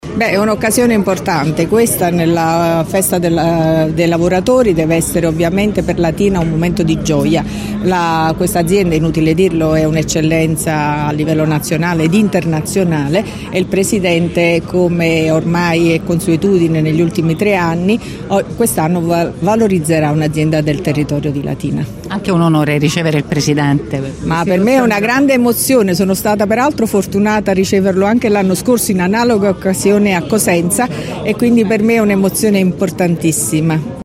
La Prefetta Vittoria Ciaramella intervistata